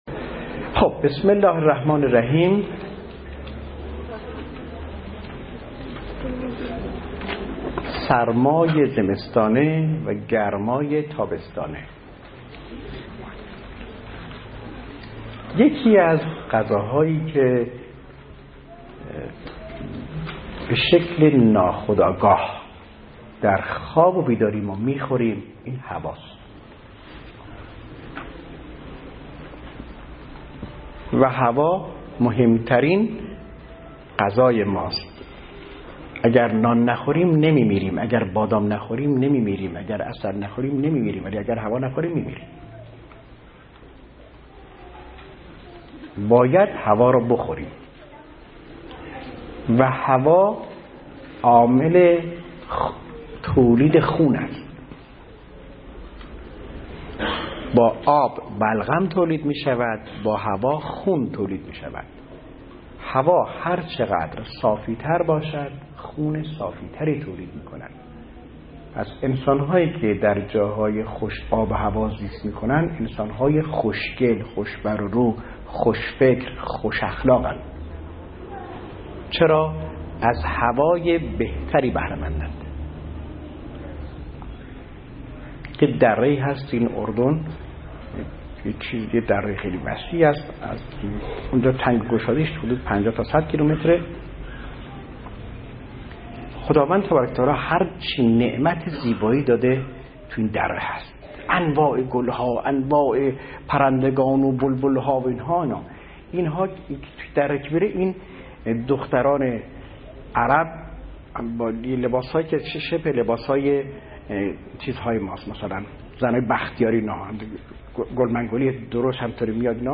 صوت جلسات طب سنتی اسلامی 19 / 9 / 95